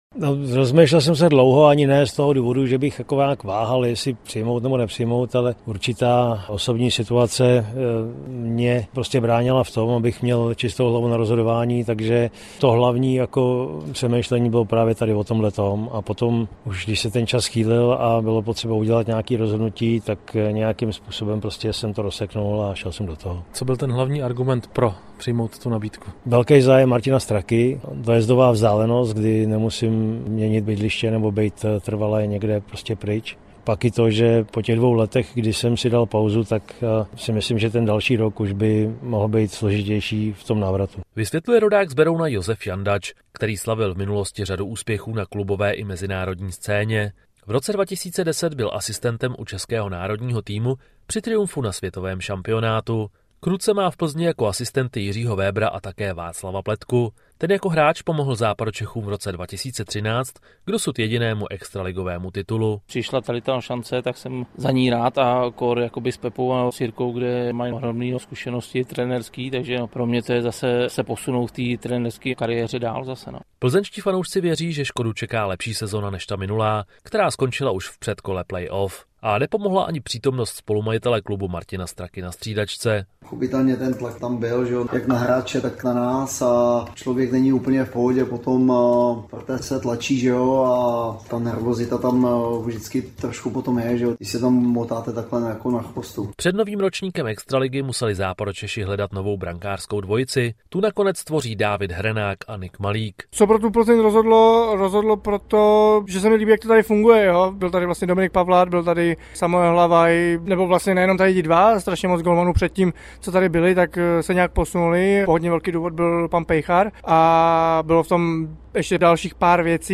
Na place: Hosty hlavně ze sportovního prostředí zvou do studia přední čeští herci známí např. z rolí v seriálu a filmu Okresní přebor a sportovní fanoušci - 04.09.2024